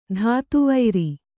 ♪ dhātu vairi